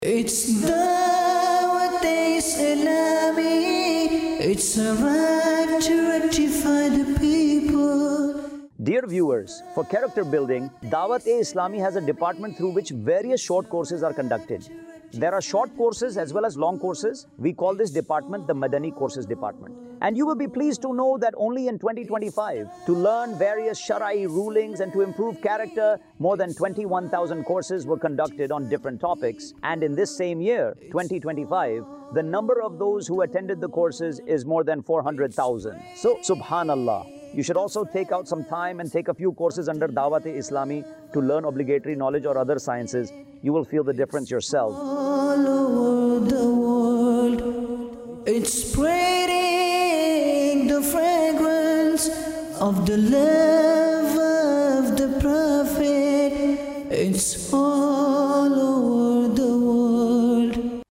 Madani Courses | Department of Dawateislami | Documentary 2026 | AI Generated Audio
مدنی کورسز | شعبہِ دعوت اسلامی | ڈاکیومینٹری 2026 | اے آئی جنریٹڈ آڈیو